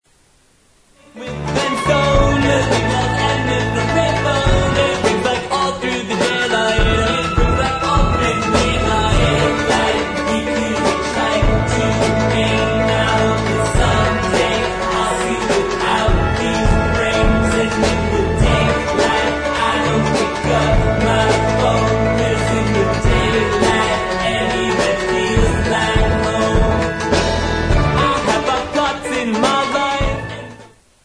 • Rock Ringtones
indie pop duo